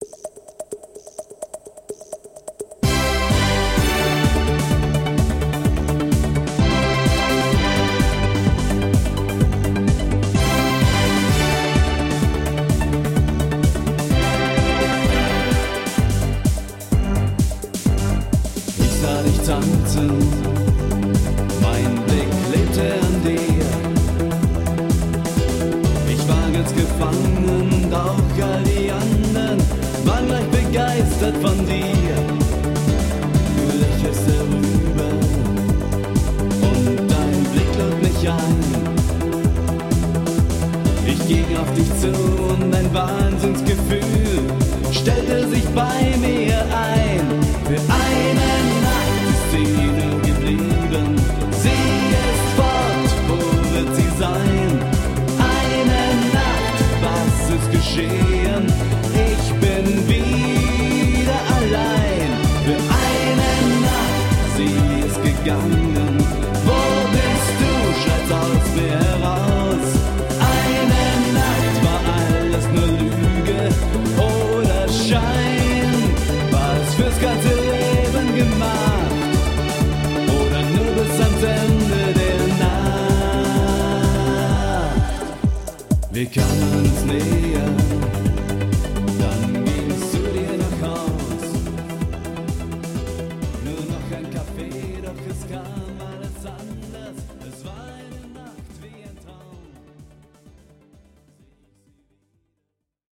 Eine Nacht (Schlager)